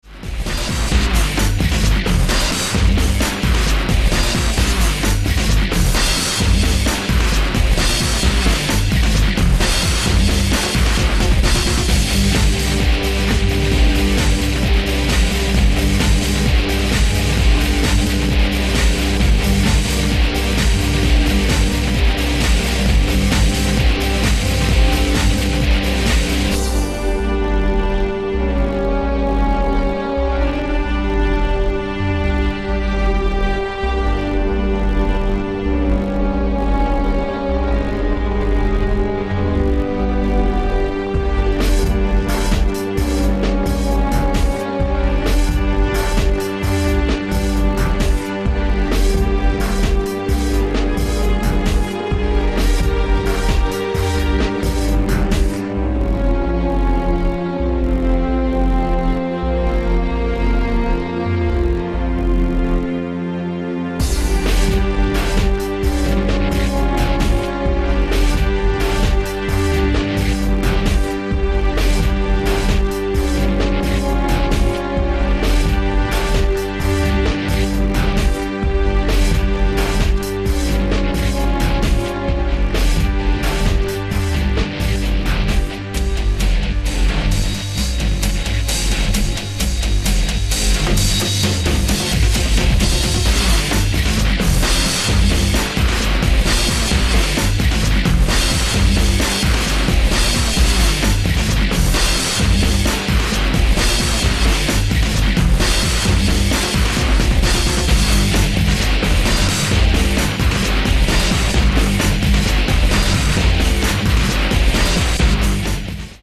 unique feel and meaning. 5 heavy guitar tracks,
Guitars played, distorted, sampled